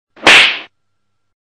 slap-sound-effect-free_tXPEW14.mp3